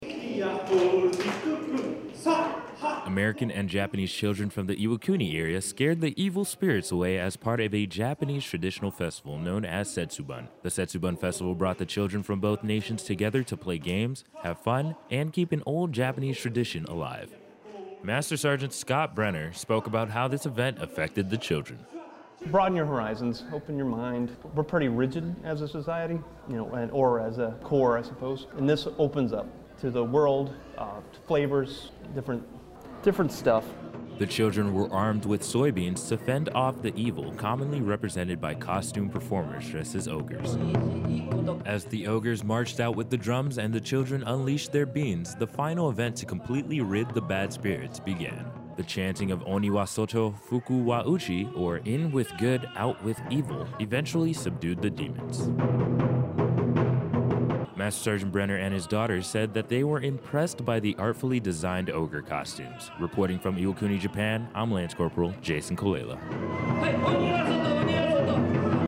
Iwakuni Radio News story